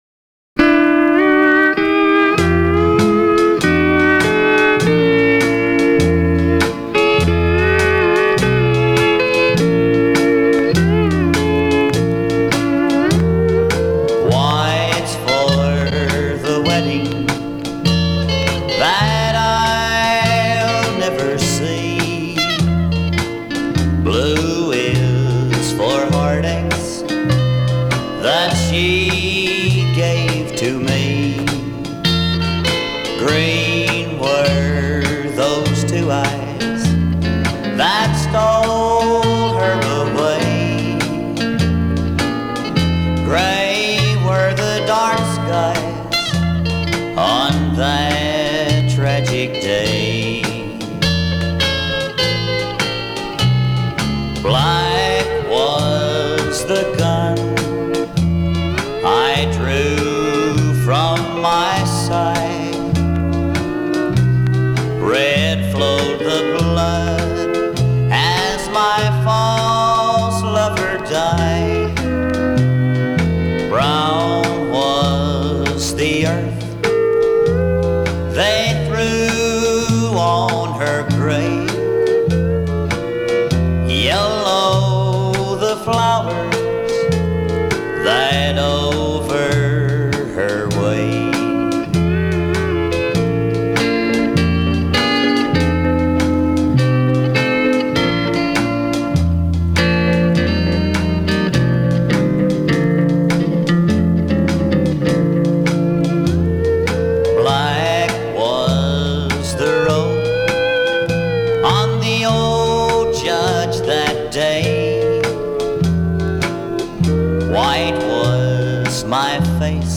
It contains some really awesome steel playing by
pedal steel
precise palm blocking and detailed intentional note slurs